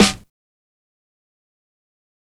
Snare Groovin 5.wav